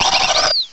cry_not_mothim.aif